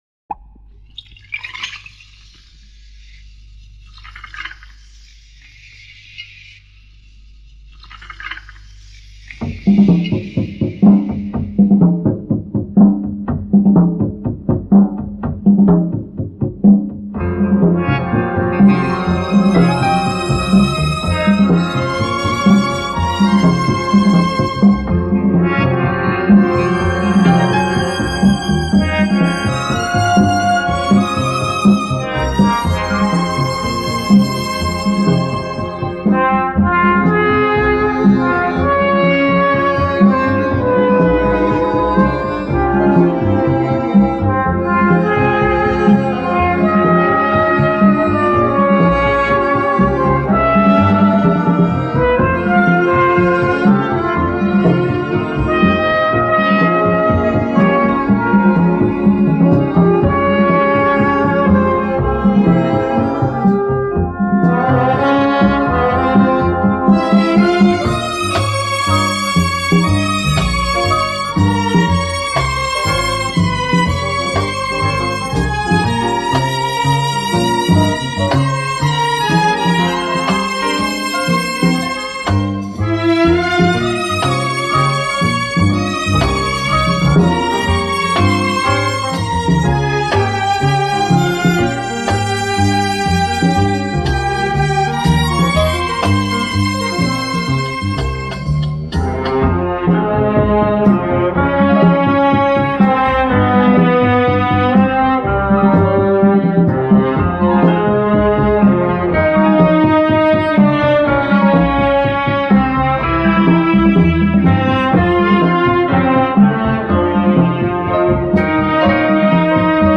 NPL 38016 Mono